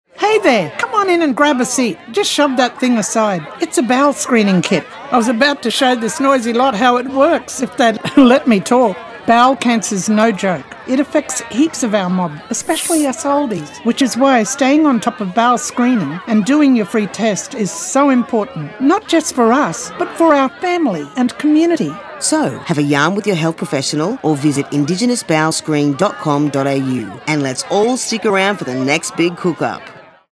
Using humour, pride in local culture, and Creole translations, we’ve created an engaging and accessible campaign.
Whether it’s sharing the importance of bowel cancer screening or reminding listeners about their health check-ups, we’ve made sure the tone reflects the spirit of the community—inviting, approachable, and relatable.